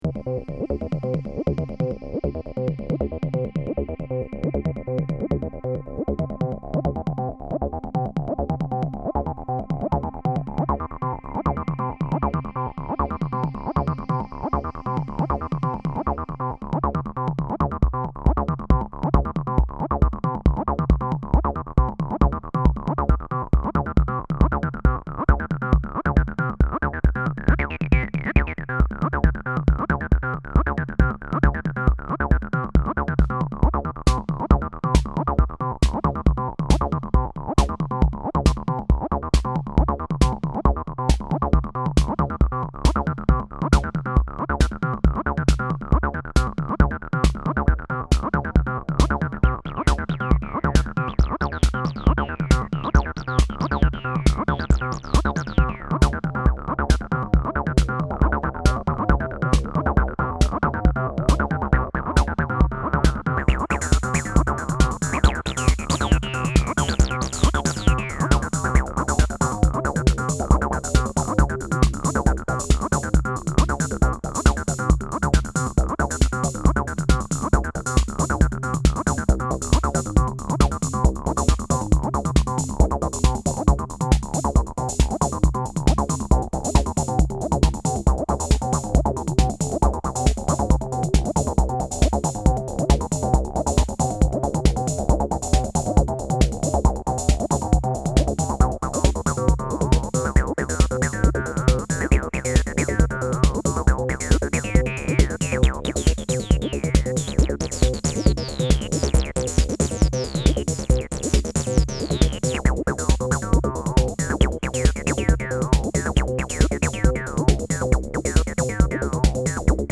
some Acid
here you’ll find some x0xb0x sounds, mostly testing the x0x or just experimental.